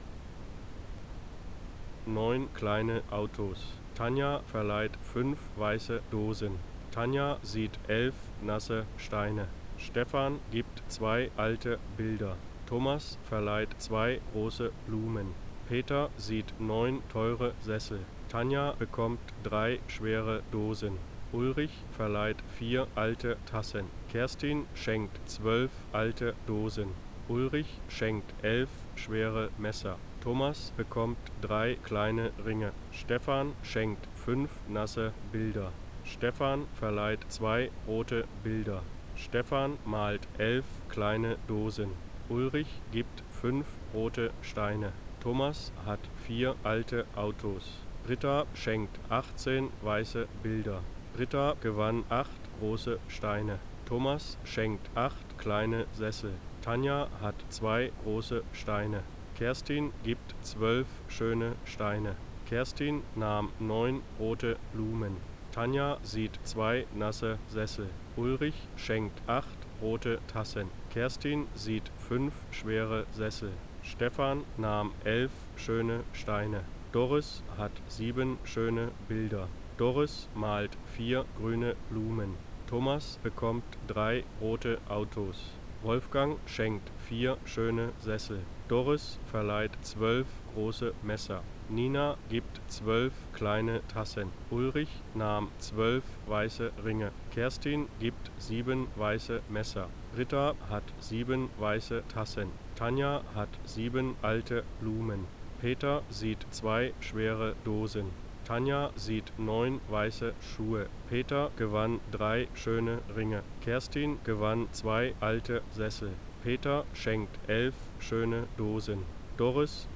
Rauschen mit Sprecher versch_SNR_R45 S60.wav